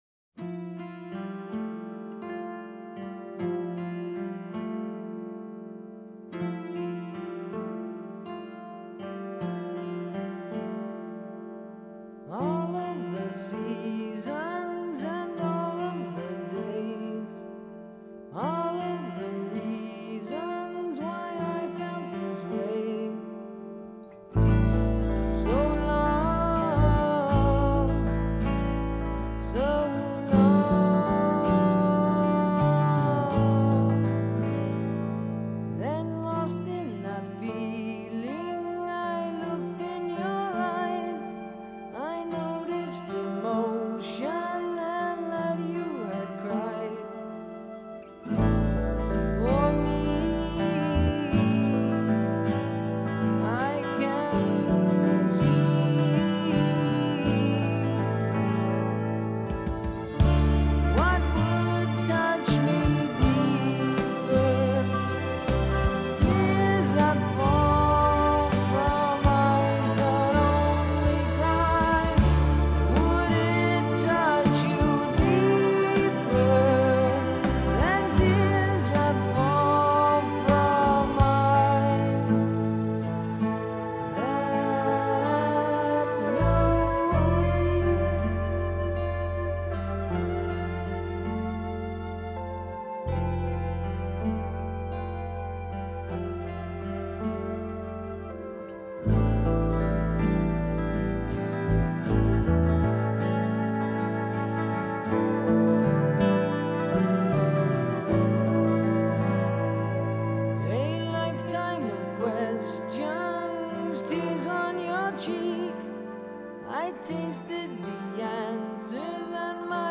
Жанр: Hard Rock, Progressive Rock